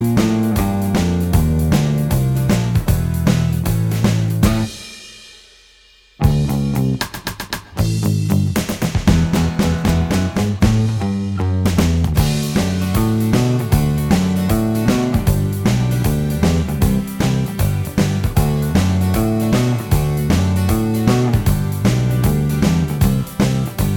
Minus Guitars Rock 2:43 Buy £1.50